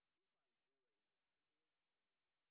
sp07_white_snr0.wav